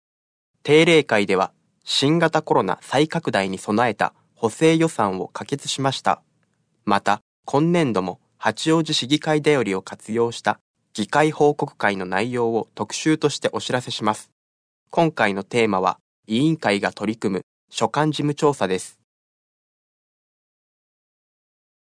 「声の市議会だより」は、視覚に障害のある方を対象に「八王子市議会だより」を再編集し、音声にしたものです。